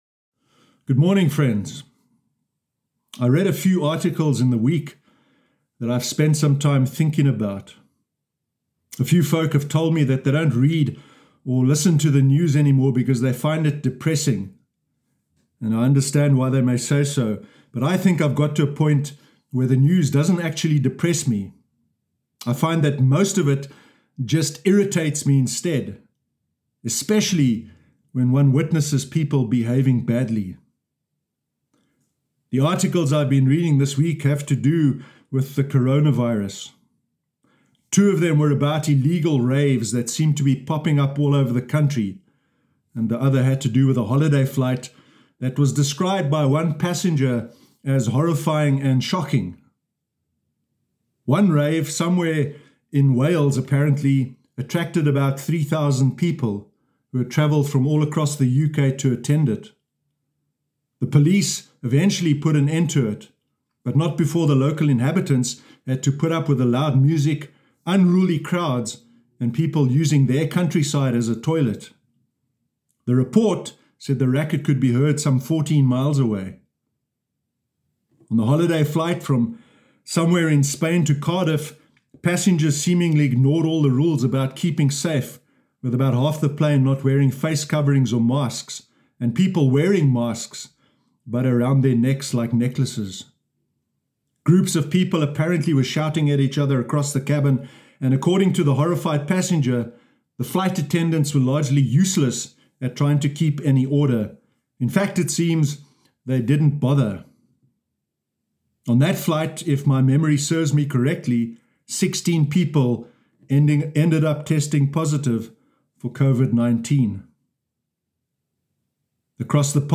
Sermon Sunday 6 September 2020
sermon-sunday-6-september-2020.mp3